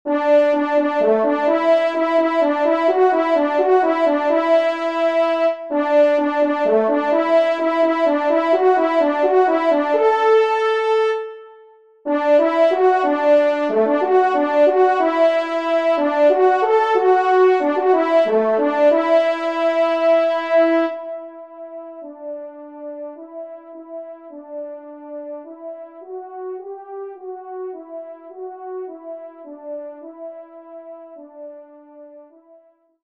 Genre :  Divertissement pour Trompes ou Cors
1ère Trompe